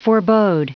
Prononciation du mot forebode en anglais (fichier audio)
Prononciation du mot : forebode